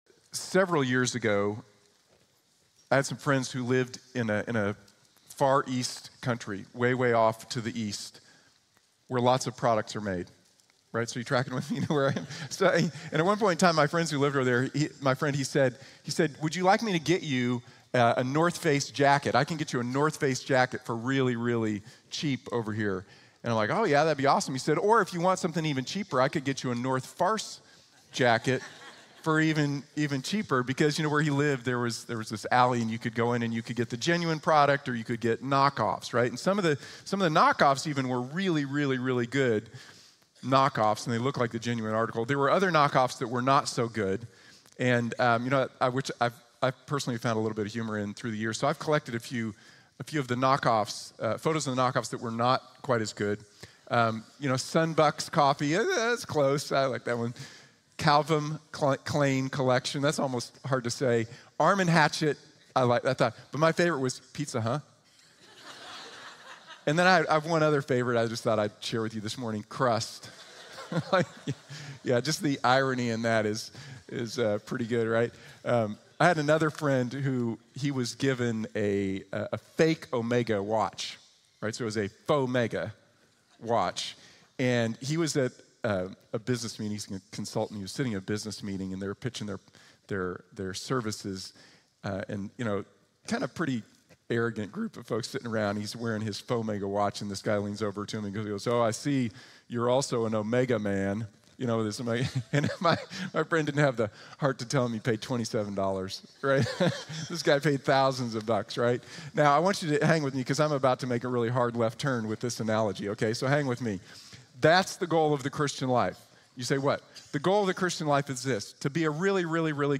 Be Like Jesus | Sermon | Grace Bible Church